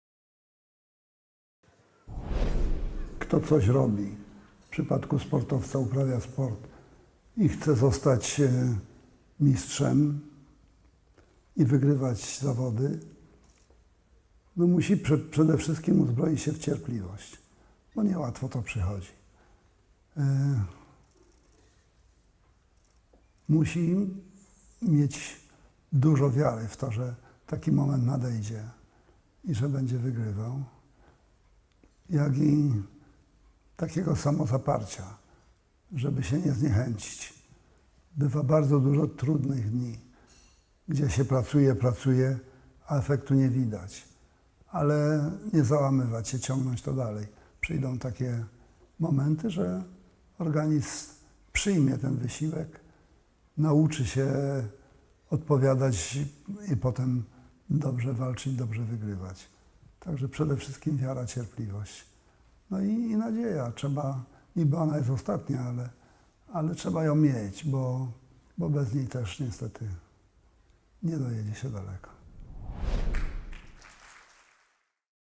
Rok temu w wywiadzie dla Telewizji Polskiej tak mówił o swojej sytuacji, a dokładnie miesiąc temu na Gali Mistrzów Sportu zostawił nam ostatnie życiowe wskazówki.